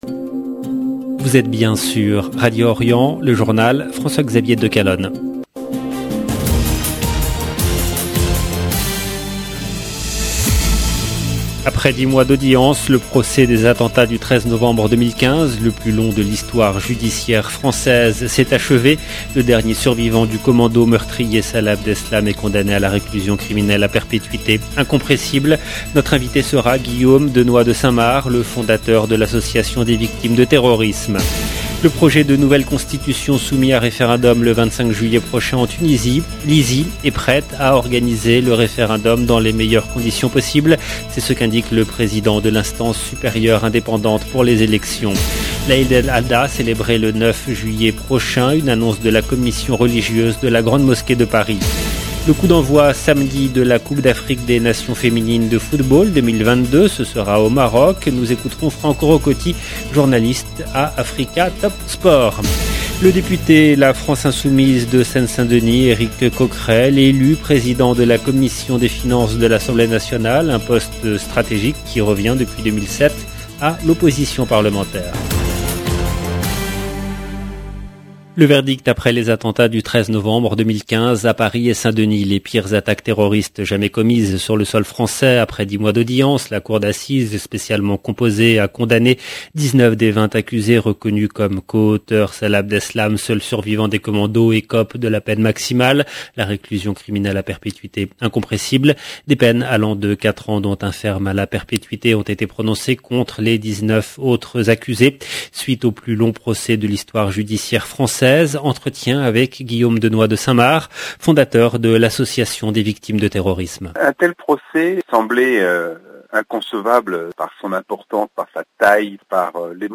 LE JOURNAL DU SOIR EN LANGUE FRANCAISE DU 30/6/2022